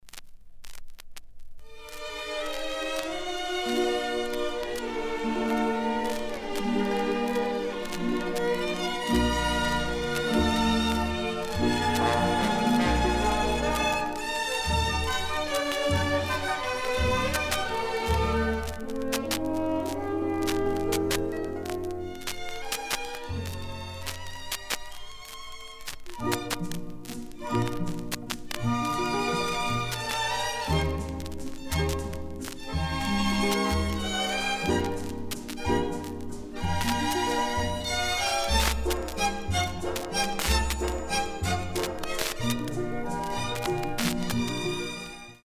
Diese Schallplatte hatte einiges durchgemacht. Kratzer, Staub, Fingerabdrücke und Flecken.
klassik_original.mp3